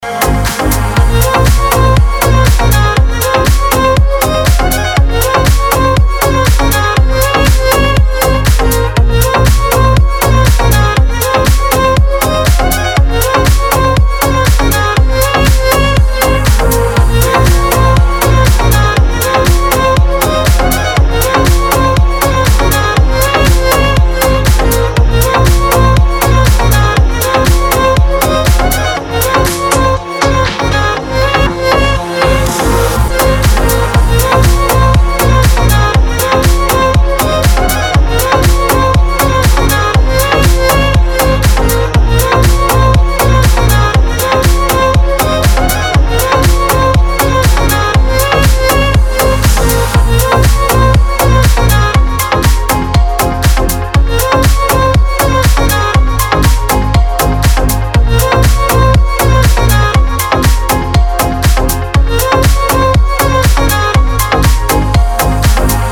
• Качество: 320, Stereo
громкие
восточные мотивы
скрипка
Стиль: deep house